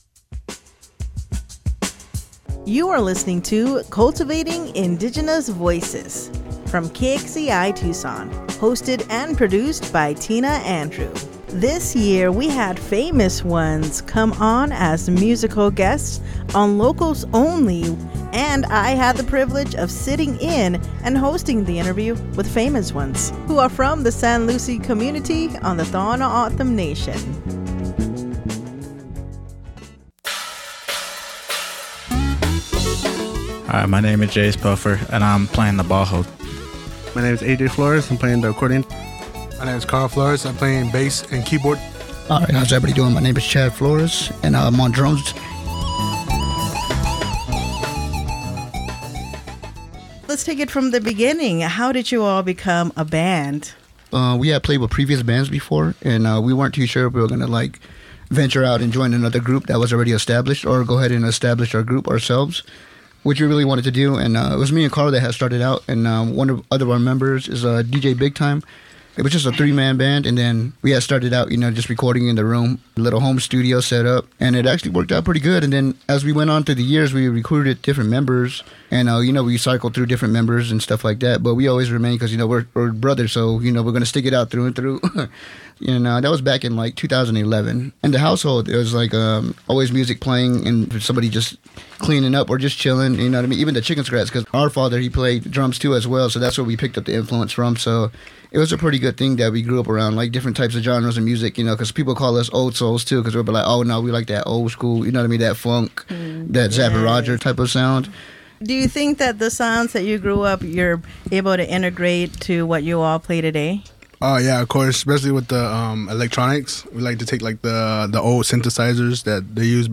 Here is a brief version of the interview that offers key highlights and insights from the full conversation.